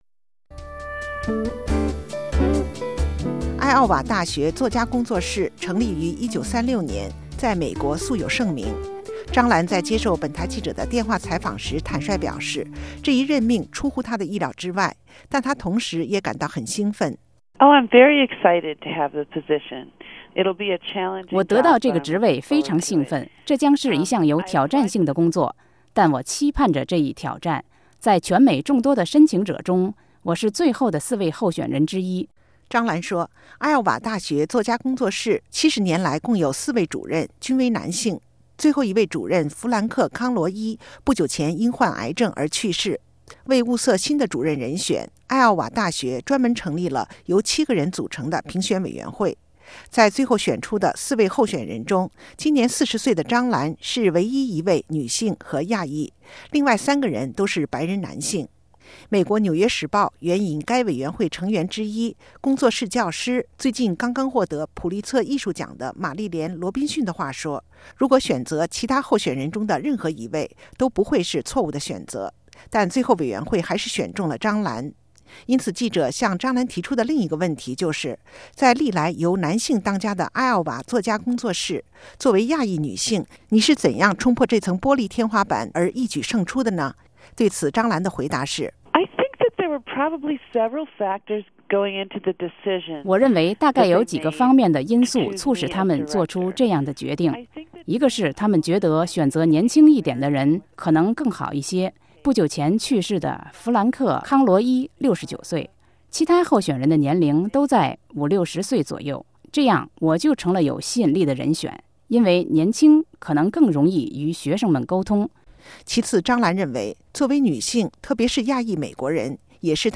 But as she told RFA's Mandarin service in a recent interview, Chang prefers to leave personal attributes behind when it comes to a writer's work.